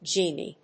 ジーニー